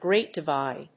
アクセントGréat Divíde